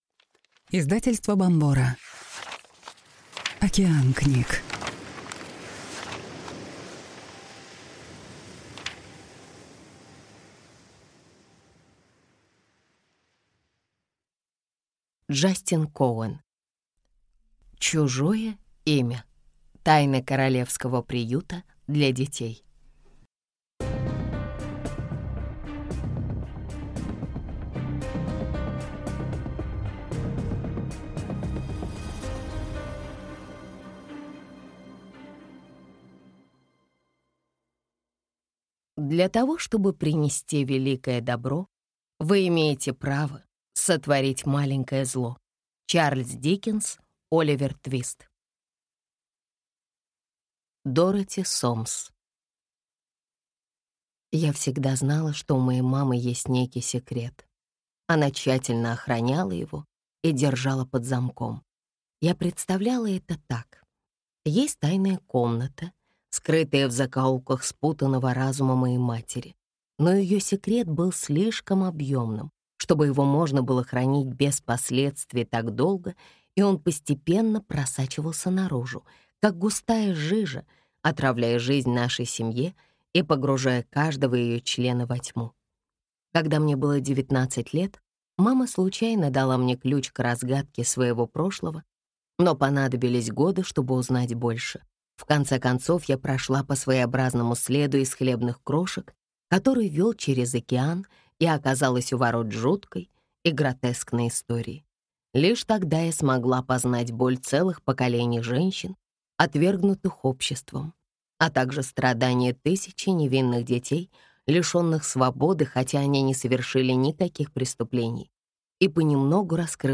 Аудиокнига Чужое имя. Тайна королевского приюта для детей | Библиотека аудиокниг